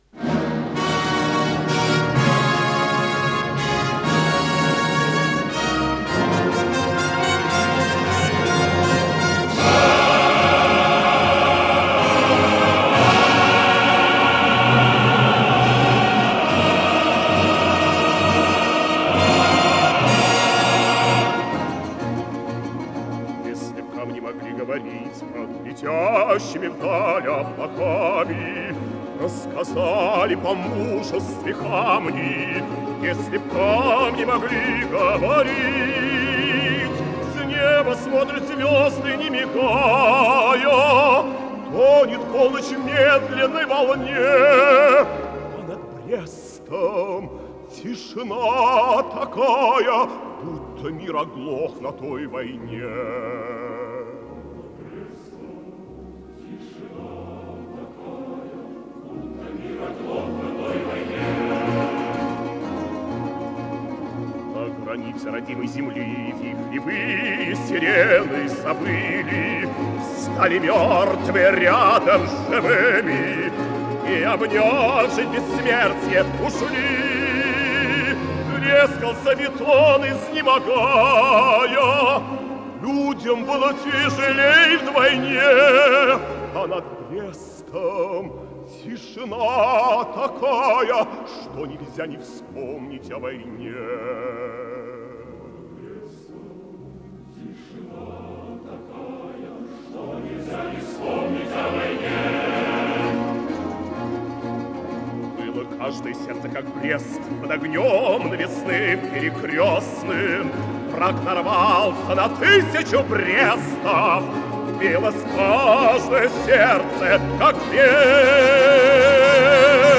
редкая запись 70-х годов.